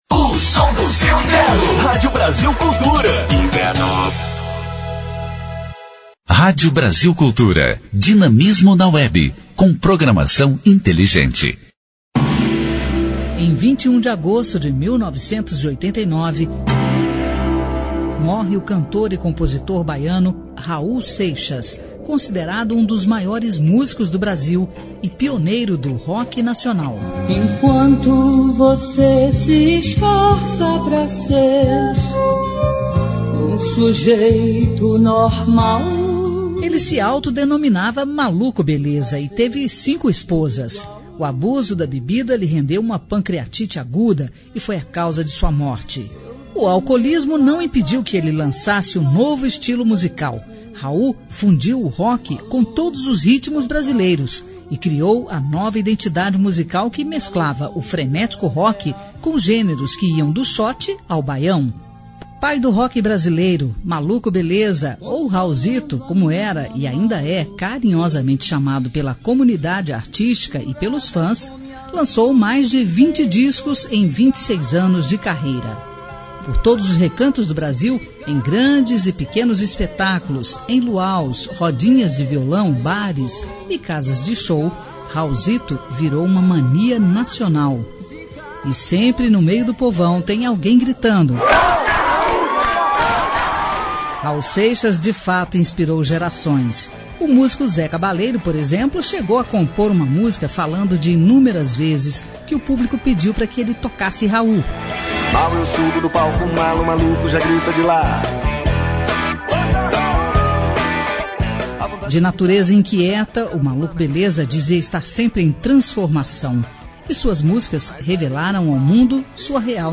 História Hoje: Programete sobre fatos históricos relacionados às datas do calendário.